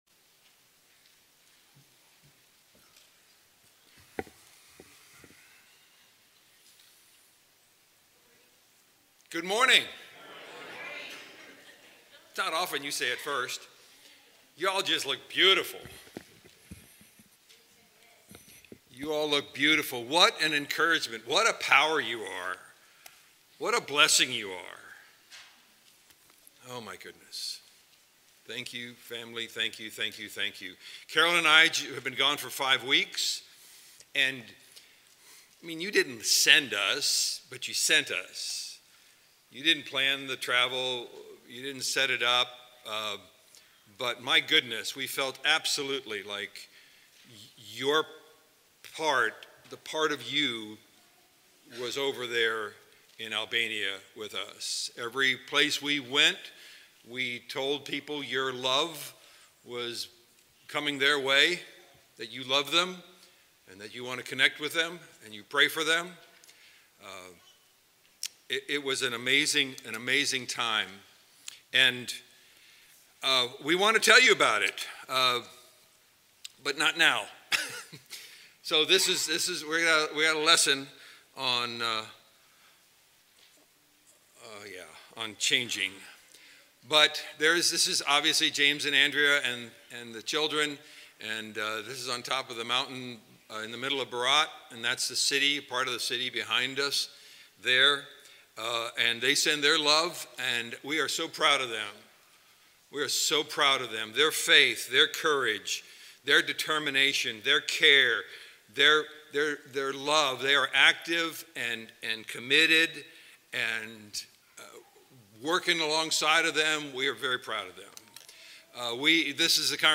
Valley church of Christ - Matanuska-Susitna Valley Alaska